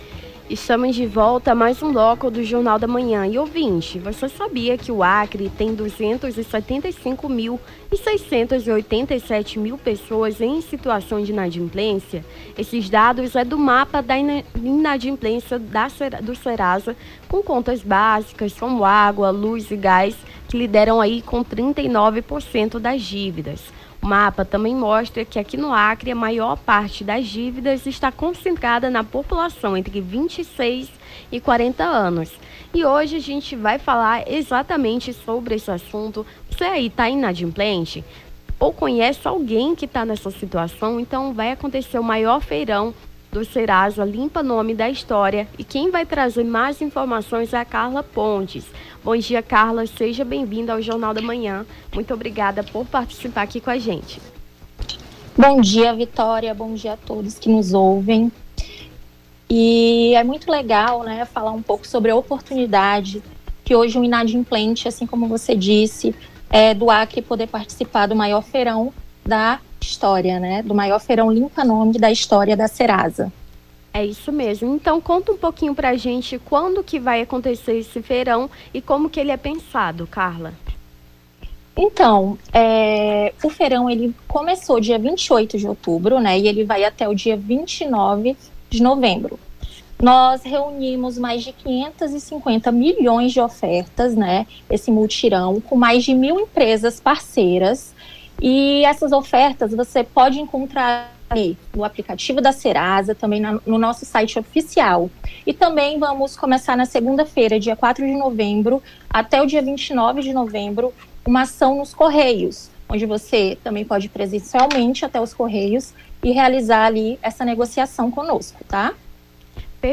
Nome do Artista - CENSURA- ENTREVISTA INADIPLÊNCIA SERASA (31-10-24).mp3